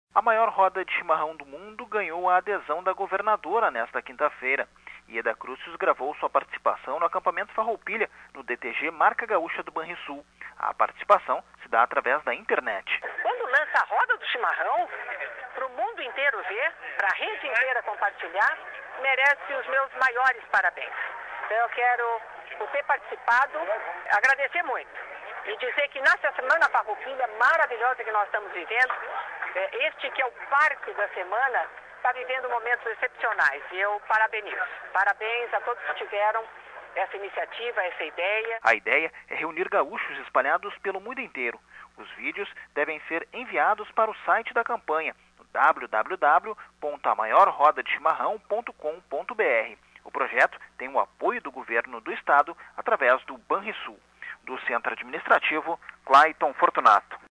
Lançada por gaúchos, a campanha para reunir a maior roda virtual de chimarrão do mundo teve a adesão da governadora Yeda Crusius, na tarde desta quinta-feria (17), no DTG Marca Gaúcha dos funcionários do Banrisul, no Parque Maurício Sirotsky